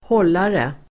Uttal: [²h'ål:are]